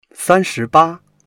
san1shi2ba1.mp3